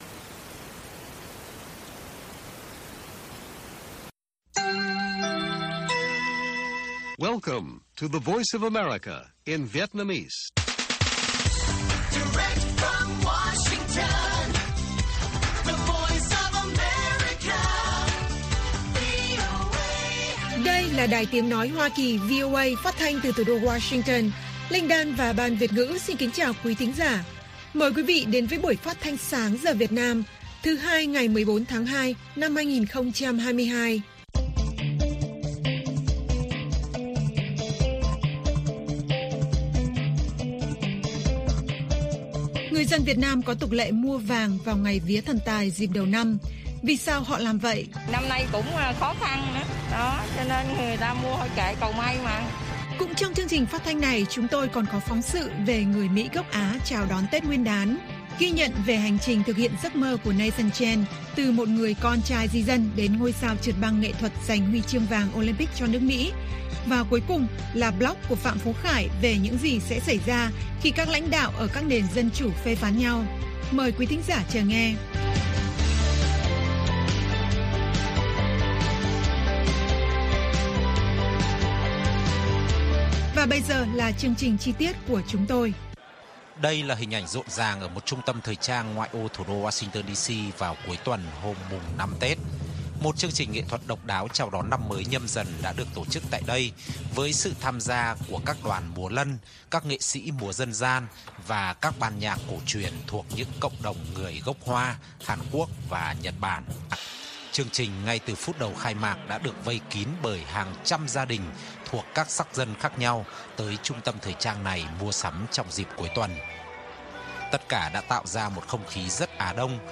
Tin tức luôn cập nhật, thời sự quốc tế, và các chuyên mục đặc biệt về Việt Nam và thế giới. Các bài phỏng vấn, tường trình của các phóng viên VOA về các vấn đề liên quan đến Việt Nam và quốc tế.